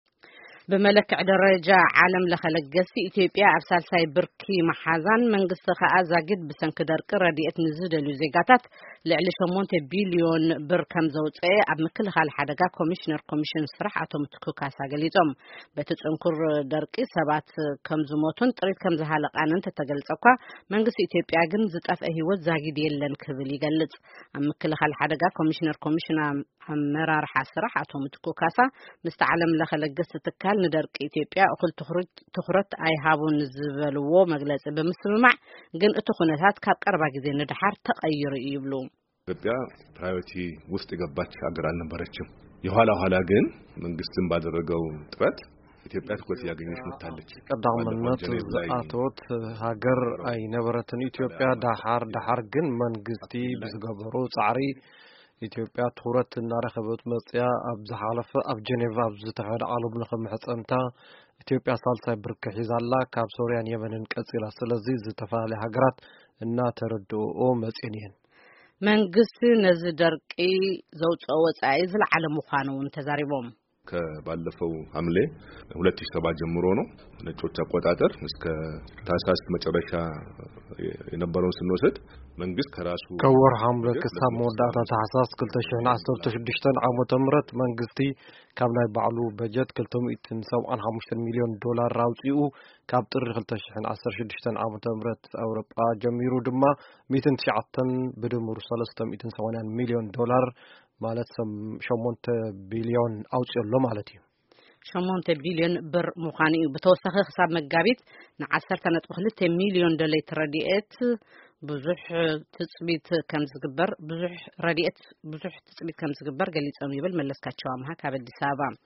ብመለክዒ ደረጃ ዓለም-ለኸ ለገስቲ ትካላት ኢትዮጵያ 3ይ ደረጃ ምሓዛን መንግስቲ ኸአ ዛጊት ብሰንኪ ደርቂ ሓገዝ ንዘደልዮ ዜጋታት ልዕሊ 8 ሚሊዮን ብር ከምዝወጽአን ኣብ ምኽልካል ሓደጋ ኮሚሽነር ኮሚሽን ኣመራርሓ ስራሕ ኣቶ ምትኩ ካሳ ንድምጺ ኣሜሪካ ገሊጾም።